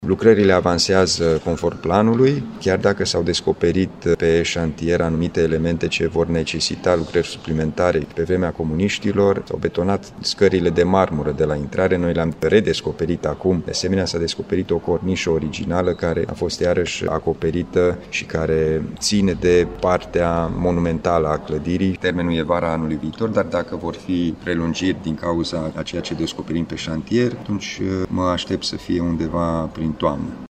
Este posibil însă să apară întârzieri din cauza unor elemente descoperite pe parcurs, spune președintele Consiliului Județean Timiș, Alin Nica.